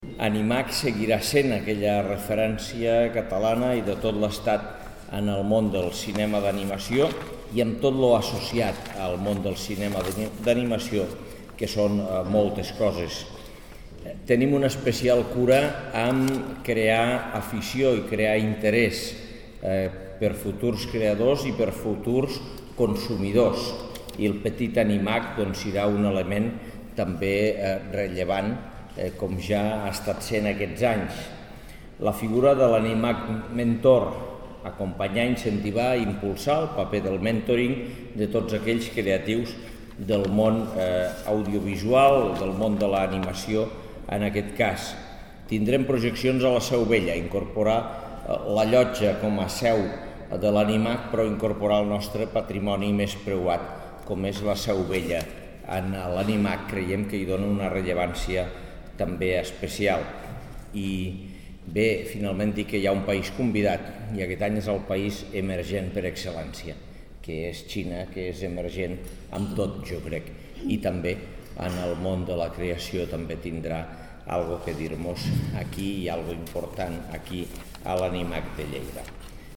(1.9 MB) Tall de veu de l'alcalde de Lleida sobre l'edició 2011 d'ANIMAC (1.2 MB) Dossier de la Mostra Internacional de Cinema d'Animació de Catalunya (2.2 MB)
tall-de-veu-de-lalcalde-de-lleida-sobre-ledicio-2011-danimac